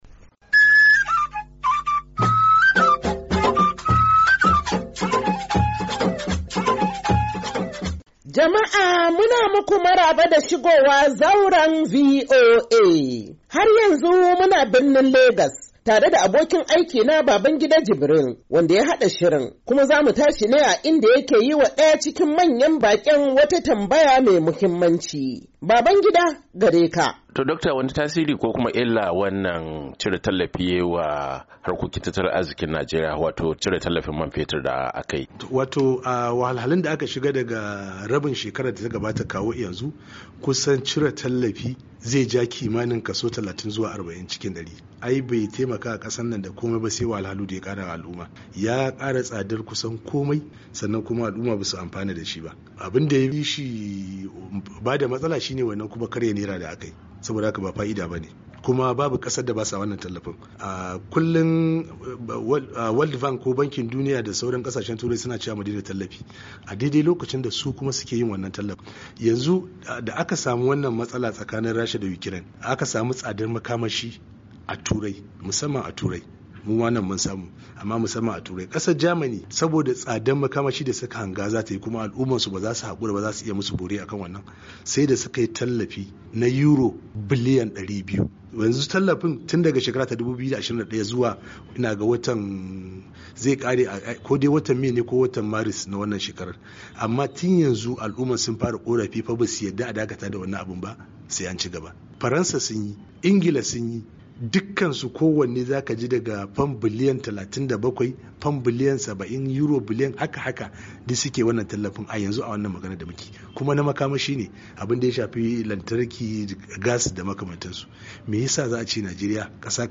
Shirin Zauran VOA na wannan mako, zai kawo muku ci gaban tattauna da masana tattalin arziki, inda suka bai wa Shugaban Najeriya Bola Ahmed Tinubu, shawara yadda za'a ingata tattalin arzikin kasar cikin gaggawa, sakamakon yadda mutane suke ci gaba da kokawa kan yadda farashin kayayyaki ke ci gaba da tashi gwauran zabi a kasar.